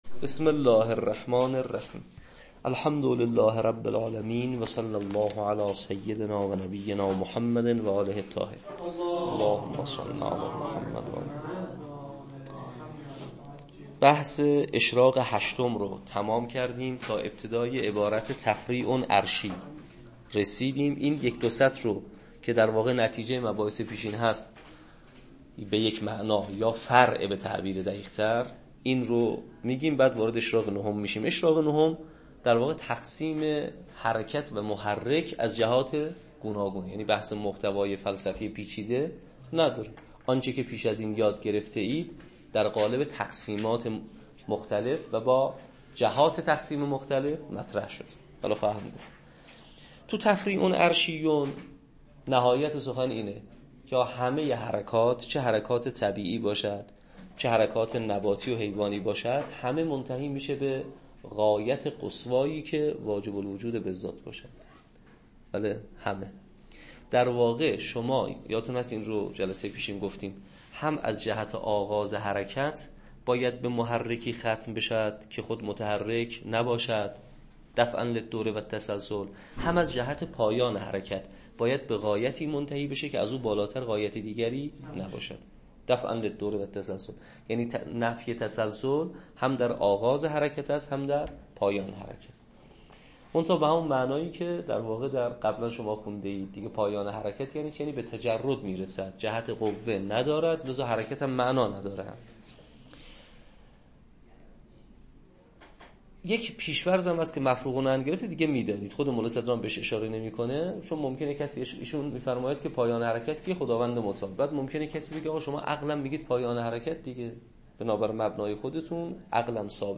شواهد الربوبیه تدریس